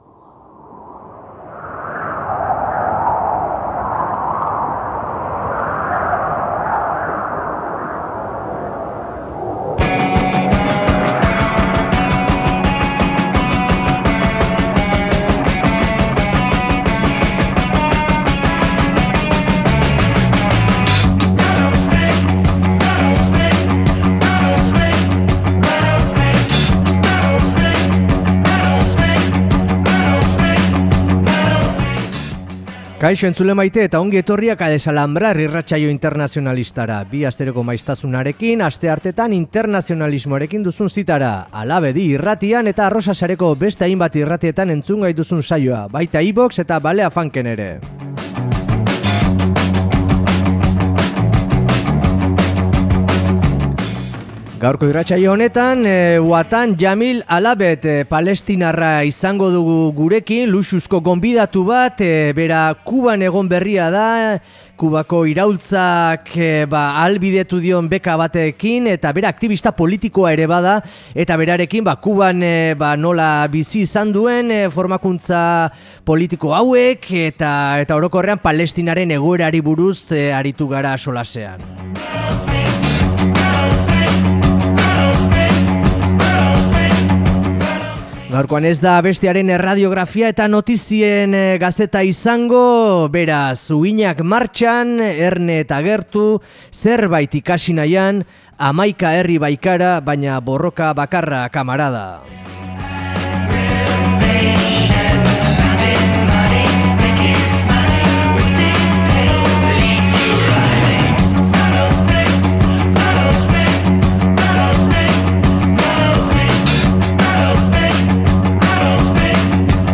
Kuban ikasi duen palestinar mediku eta aktibista elkarriizketatu dugu. Kubaz, internazionalismoaz eta, noski, Palestinaz ere aritu gara berarekin